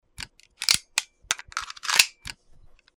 SoldierSoundsPack
reload.mp3